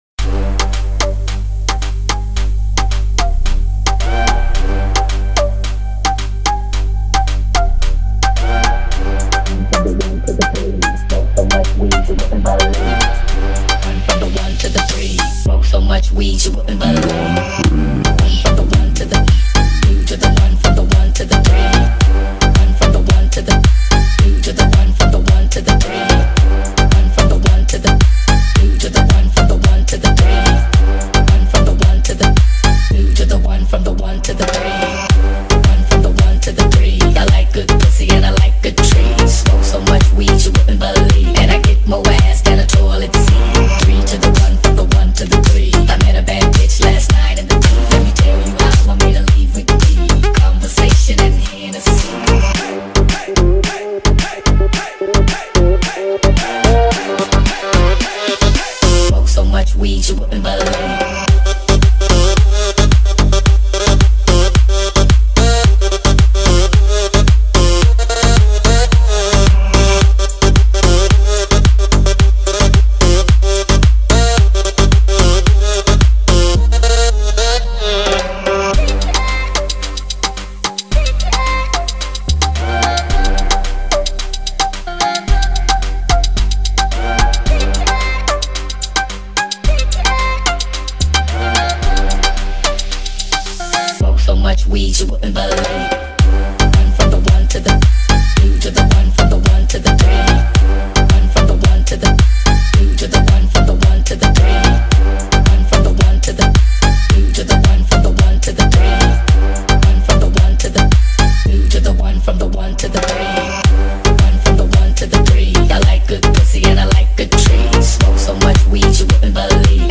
Download house remix for party